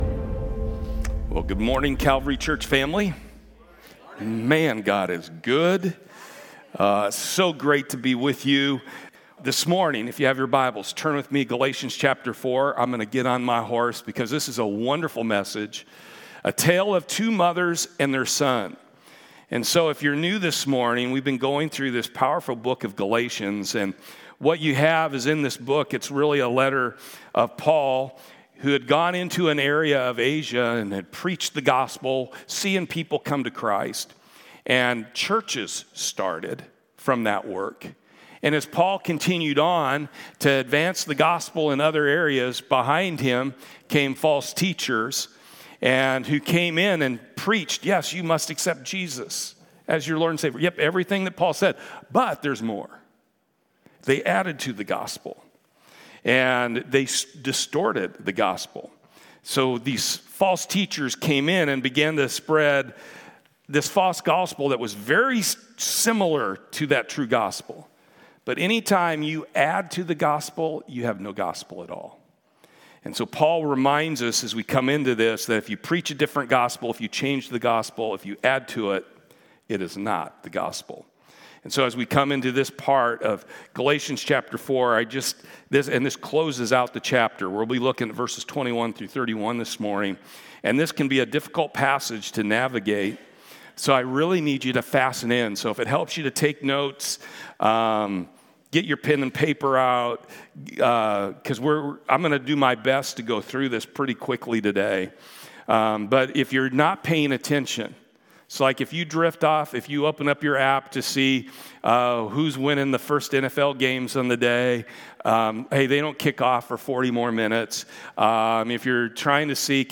Sermons
Service Type: Sunday